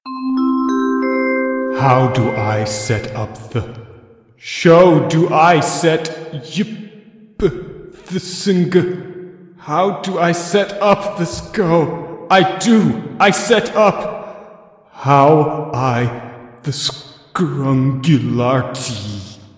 This one sounds like the original.